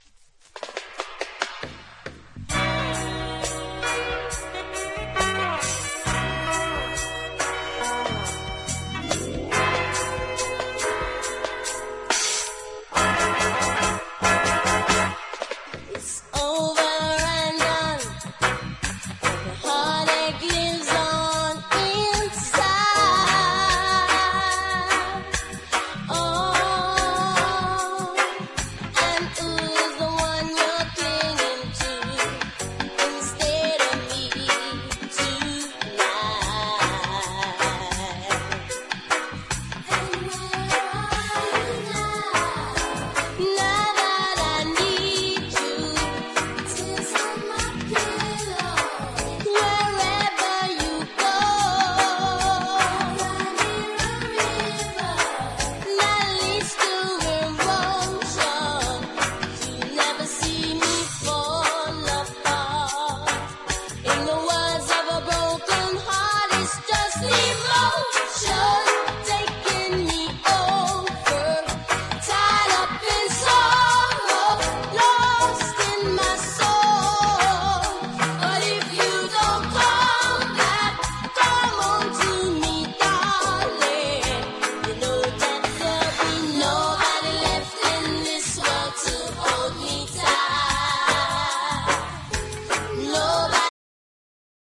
1. REGGAE >
英ラヴァーズ大人気ナンバー！！